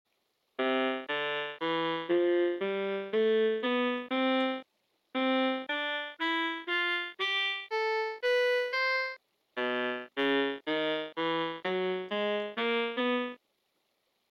Basic_Horn_Sound.mp3